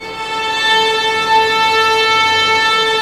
Index of /90_sSampleCDs/Roland - String Master Series/STR_Vlns Bow FX/STR_Vls Sul Pont